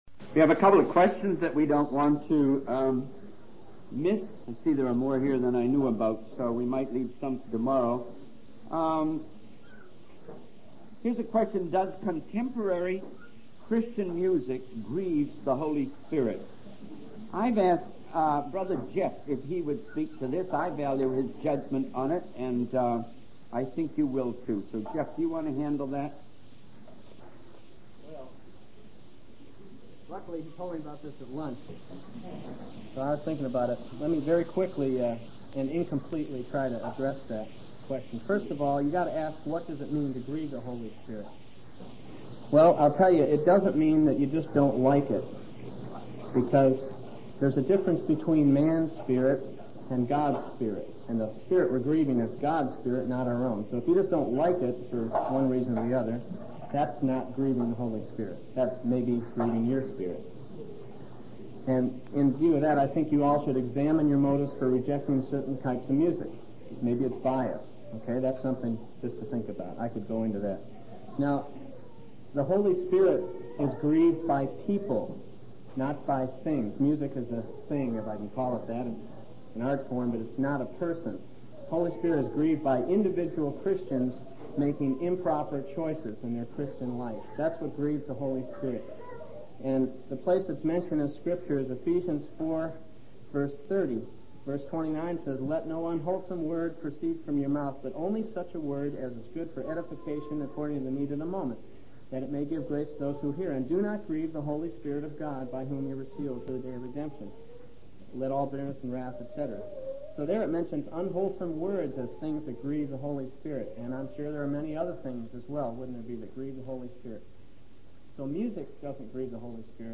In this sermon, the speaker shares a story about a woman who visited prisoners and showed them kindness by bringing them cookies and a New Testament book.